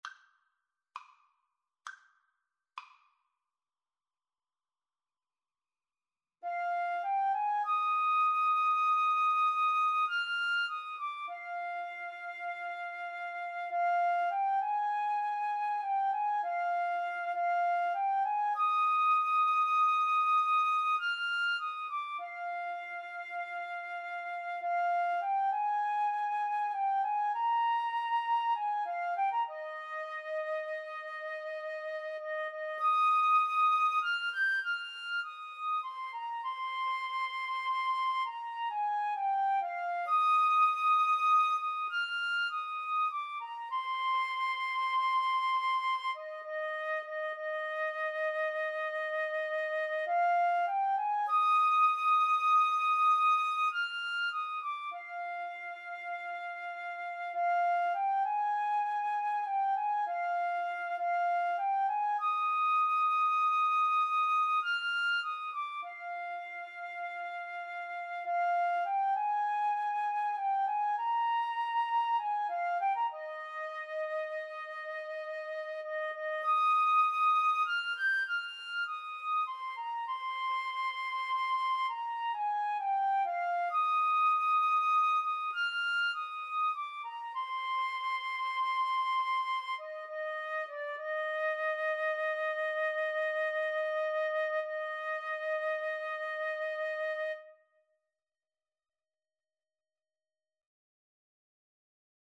FluteTenor Saxophone
6/8 (View more 6/8 Music)